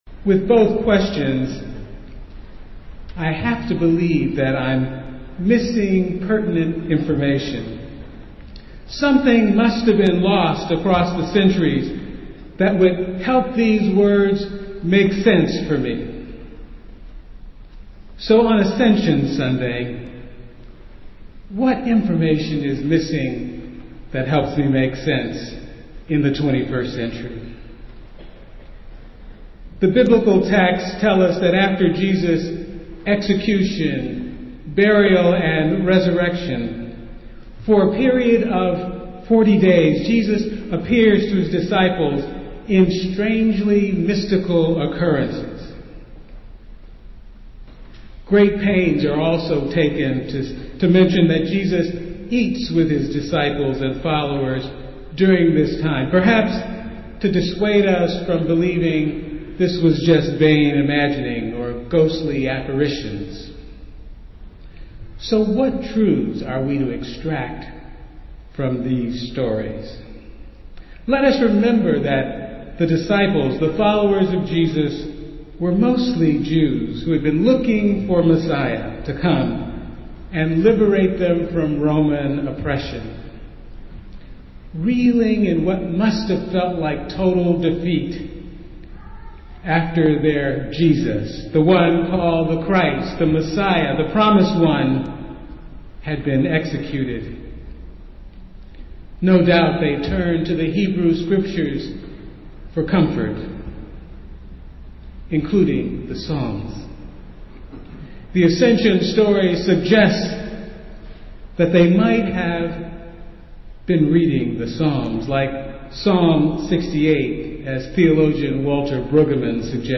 Festival Worship - Ascension Sunday